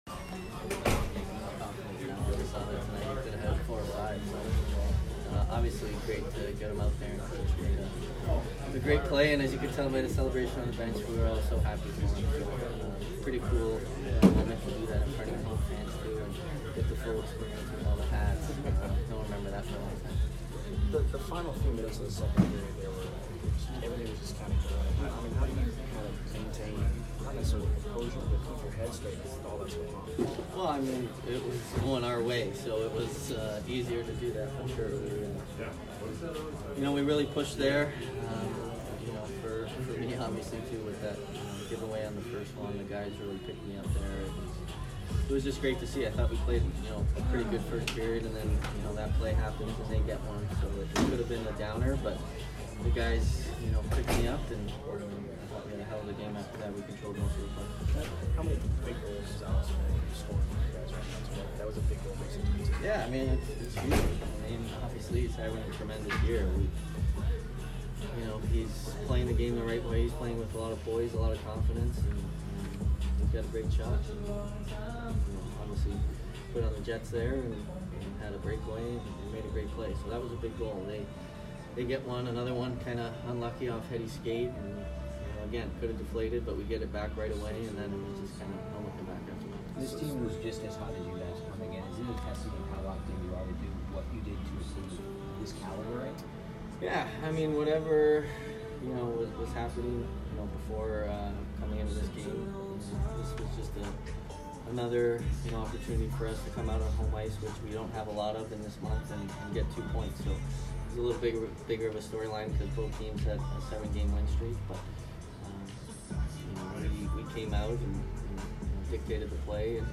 Stamkos post-game 1/7